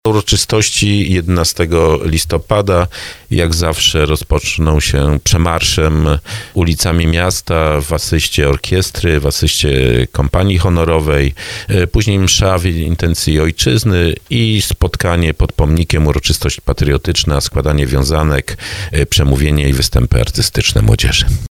mówi starosta dąbrowski Lesław Wieczorek.